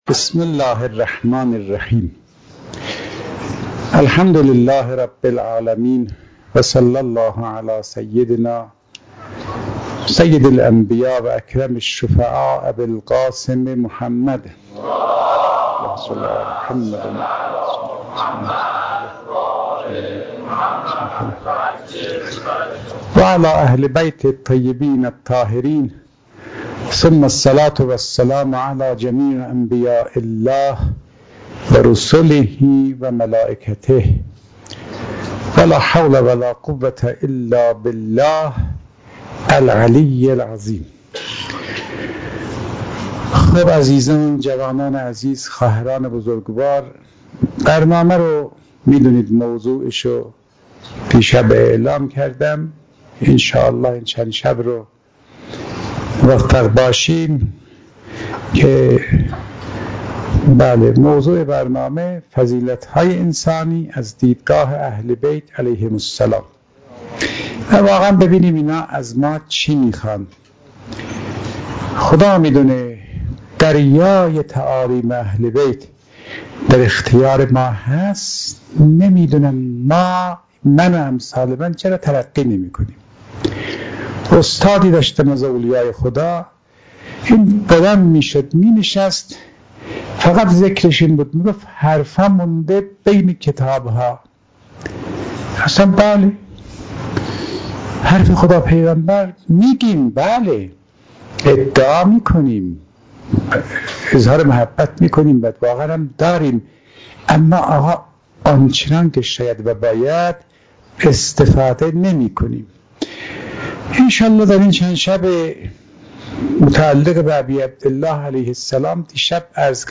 شب دوم محرم95_سخنرانی